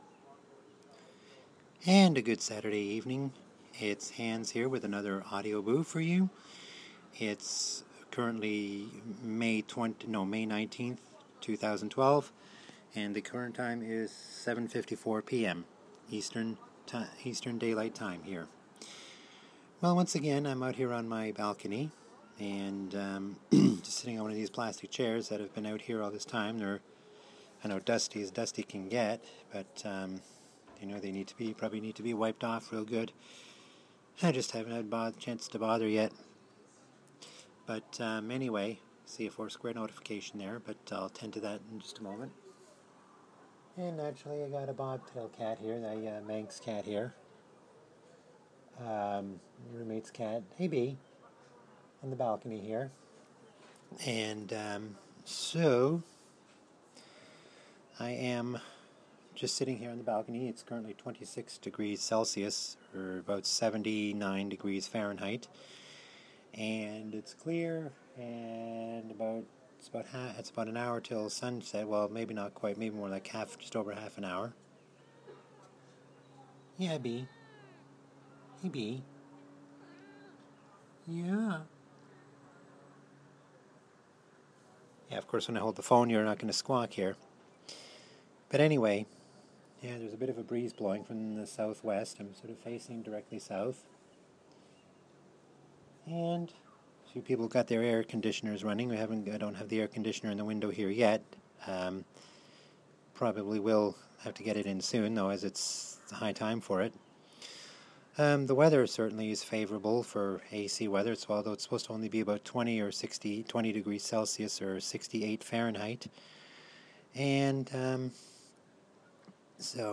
Recorded on my balcony in a lovely Saturday evening, on May 19th 2012